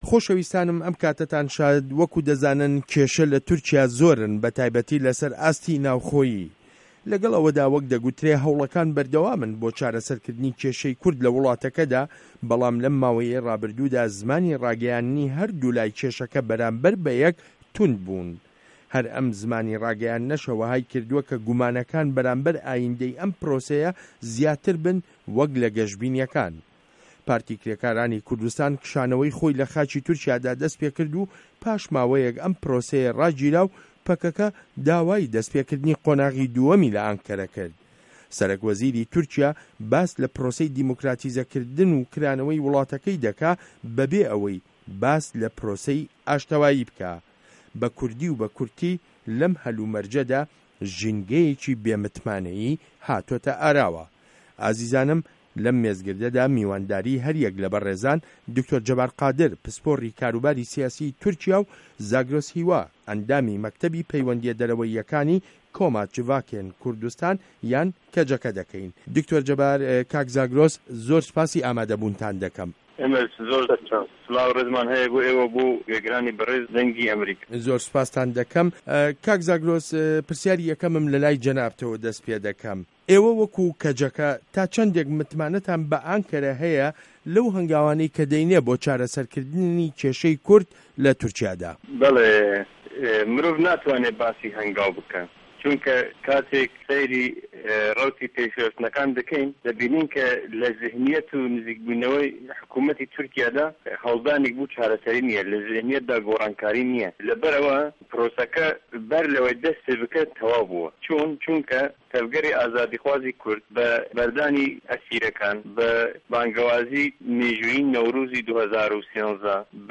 مێزگرد: تورکیا و په‌که‌که‌ و بێ متمانه‌یی له‌ پرۆسه‌ی ئاشته‌واییدا